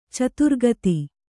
♪ caturgati